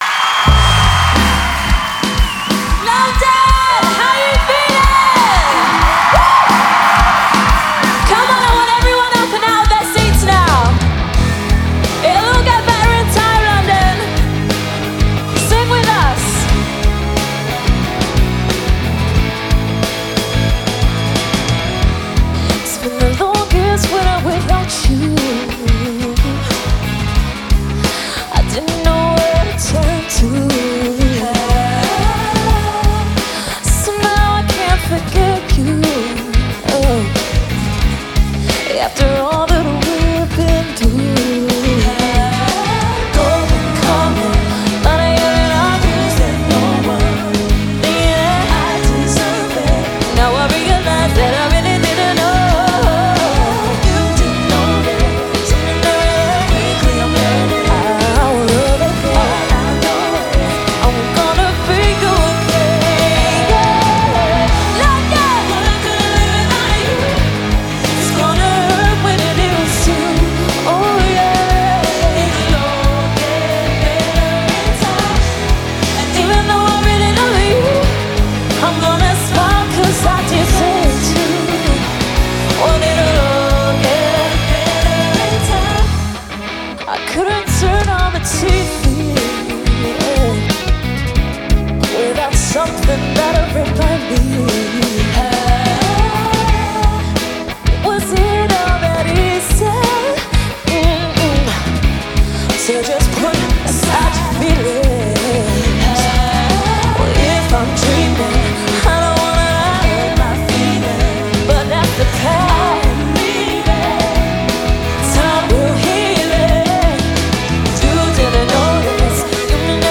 Synth-pop